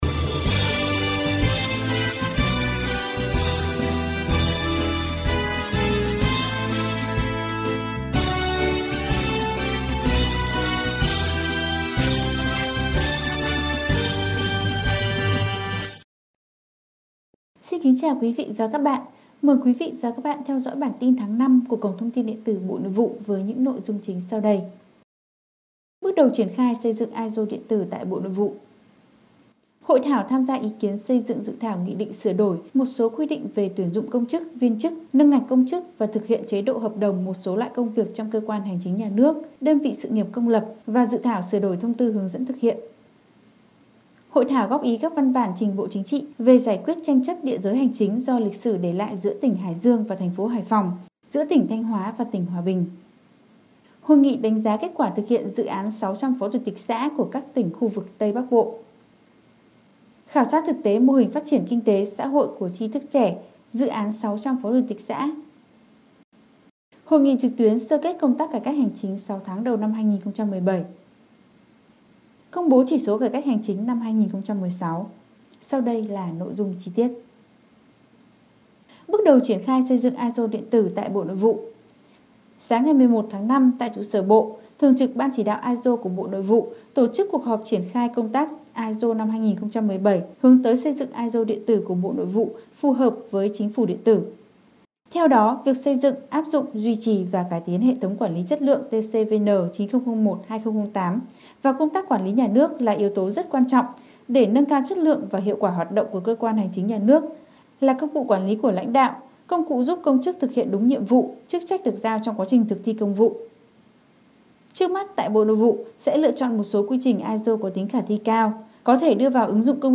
Bản tin audio ngành Nội vụ số tháng 05 năm 2018